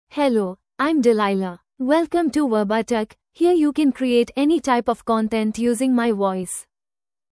FemaleEnglish (India)
Delilah is a female AI voice for English (India).
Voice sample
Female
English (India)
Delilah delivers clear pronunciation with authentic India English intonation, making your content sound professionally produced.